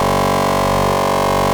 BUCHLA G2.wav